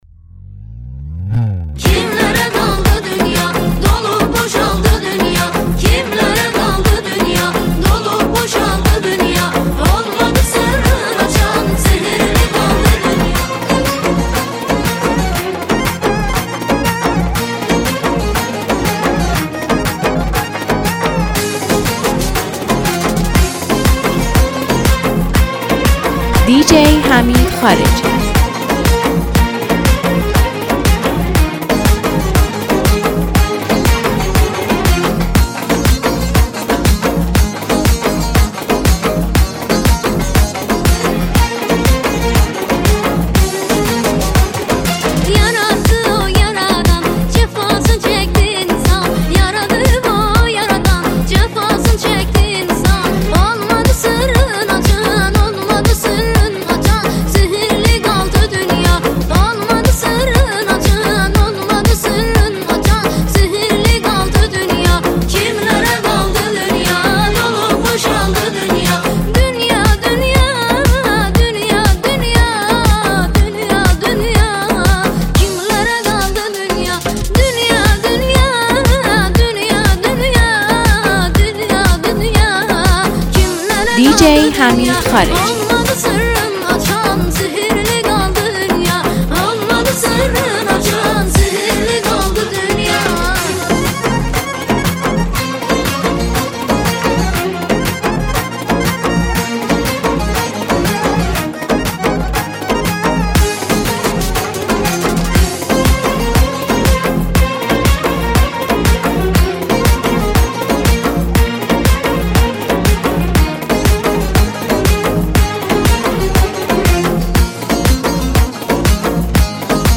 یه ریمیکس زیرخاکی و شاد برای شروع یه روز عالی!